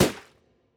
Balloon.wav